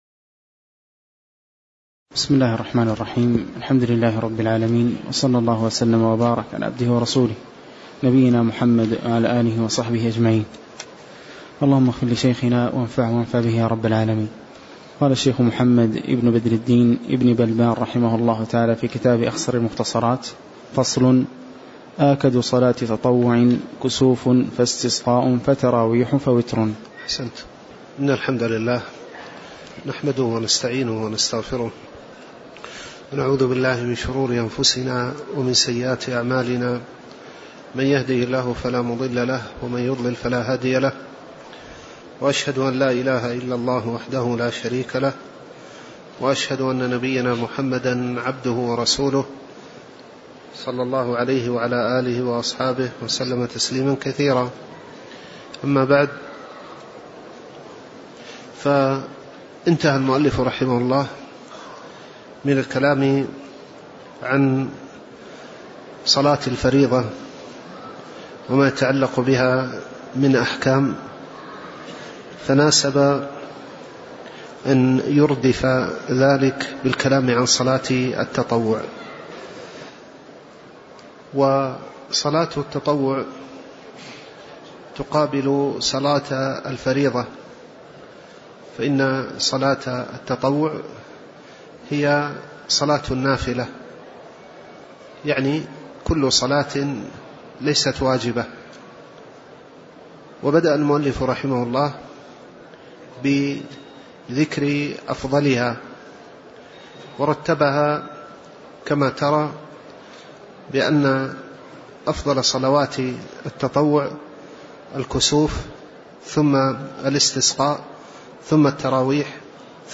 تاريخ النشر ١٩ جمادى الآخرة ١٤٣٩ هـ المكان: المسجد النبوي الشيخ